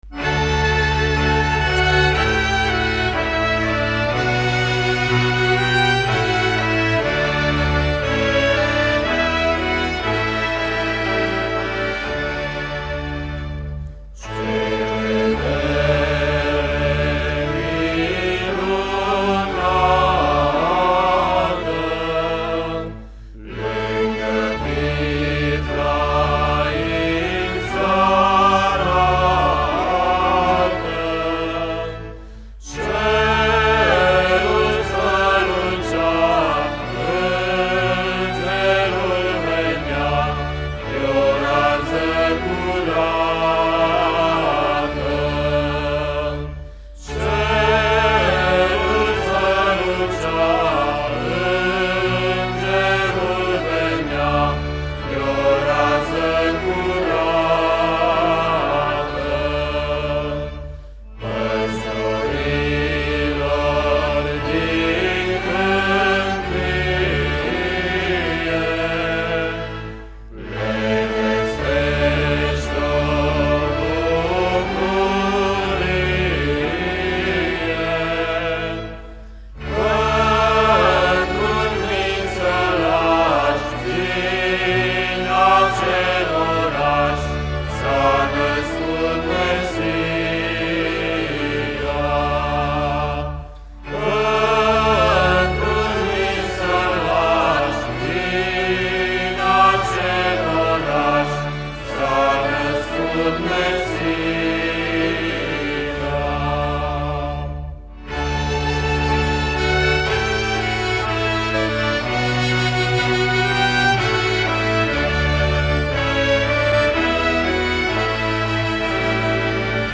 Cântec vocal religios de Crăciun